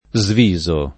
sviso [ @ v &@ o ]